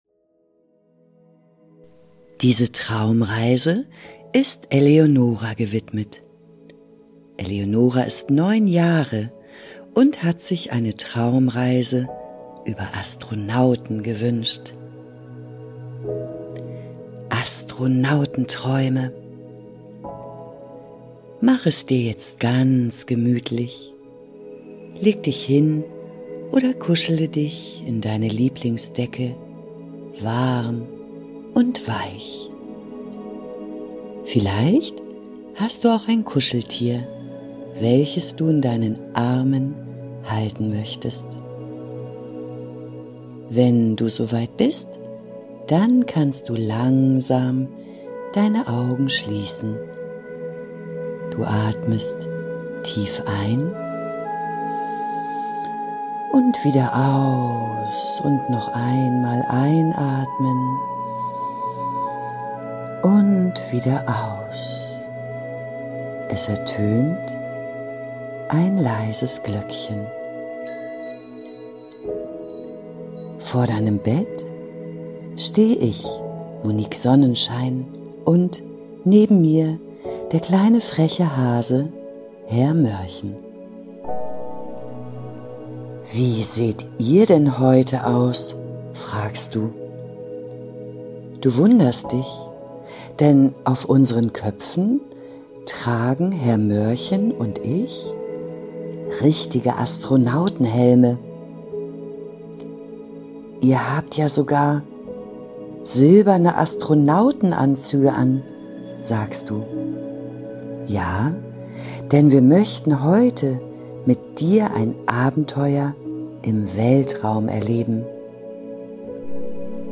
In dieser liebevoll erzählten Traumreise für Kinder und Erwachsene